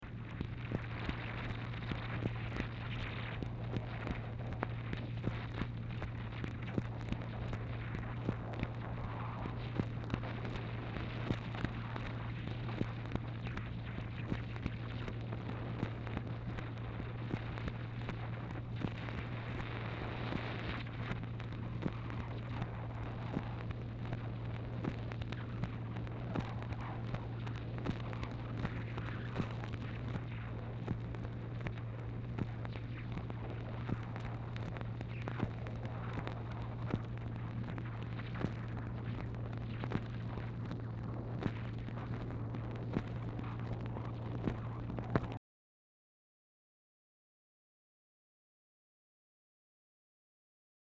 Telephone conversation # 2710, sound recording, MACHINE NOISE, 3/30/1964, time unknown | Discover LBJ
Format Dictation belt
LBJ Ranch, near Stonewall, Texas